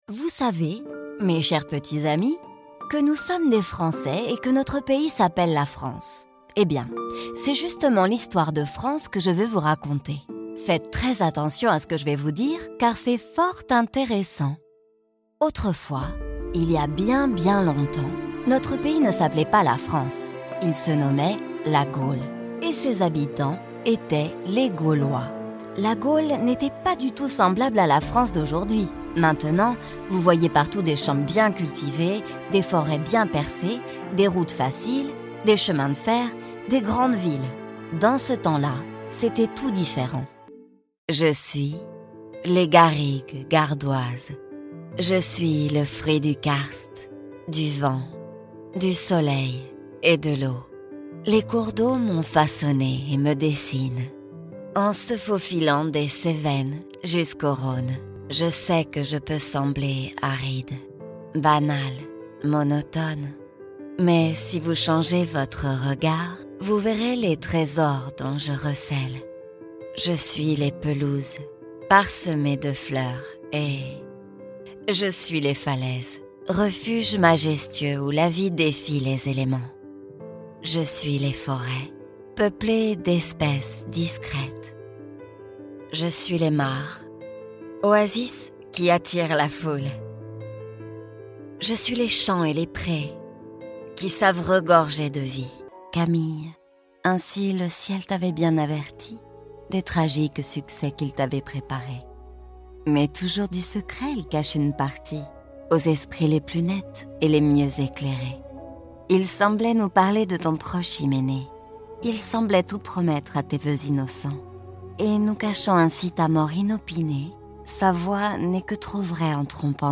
Narration
My voice is flexible and adaptable.
Home Studio Setup
Microphone : RODE NT1-A